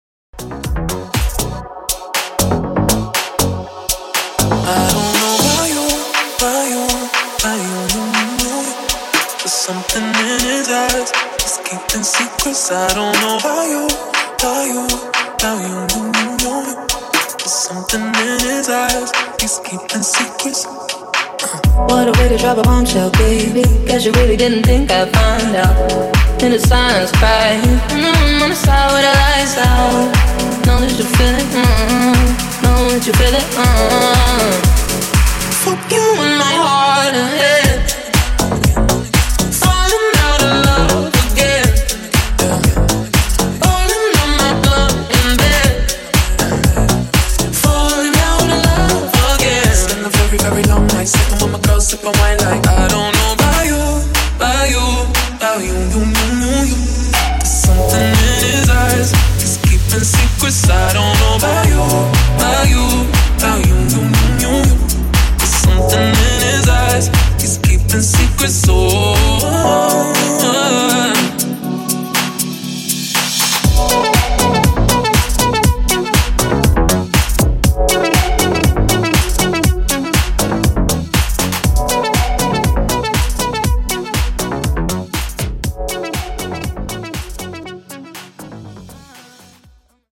Extended Mix)Date Added